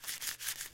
metalimpact
描述：Dropping a small firecracker onto a metal plate.
标签： paper firecracker drop metal fieldrecording hit stereo plate impact
声道立体声